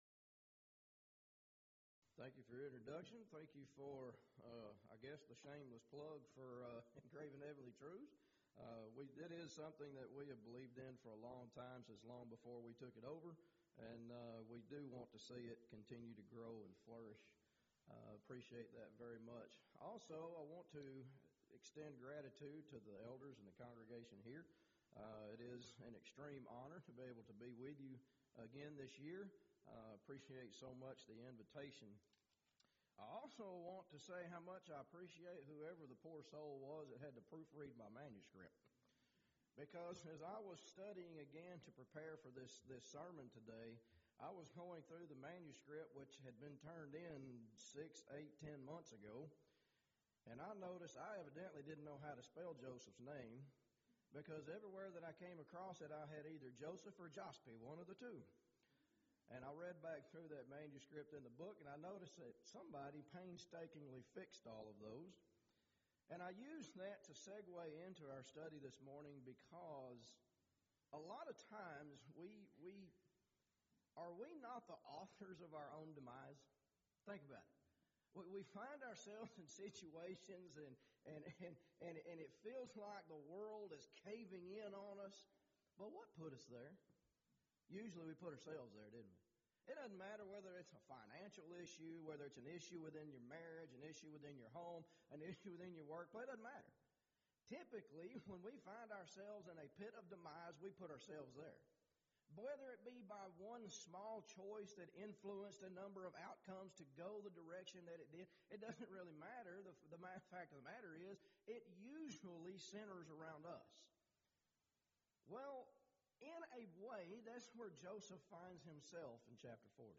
Event: 16th Annual Schertz Lectures
lecture